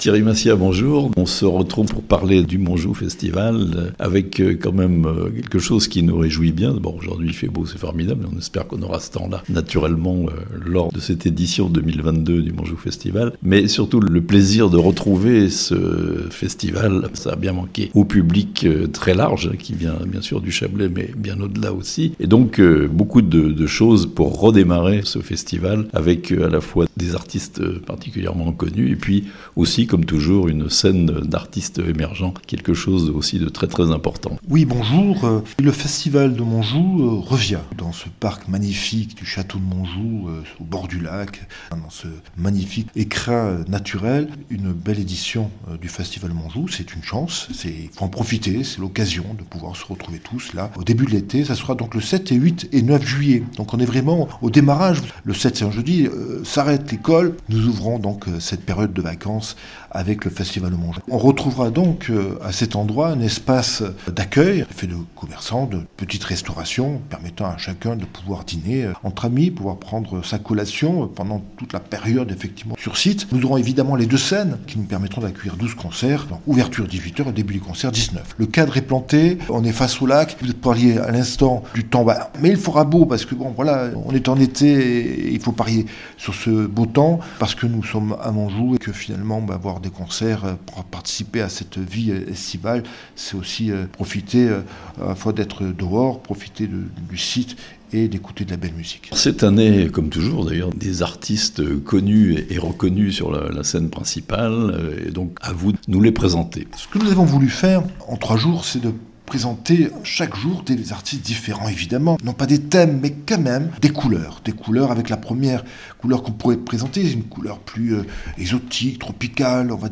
Montjoux Festival 2022 à Thonon, le retour après 2 ans d'absence (interview)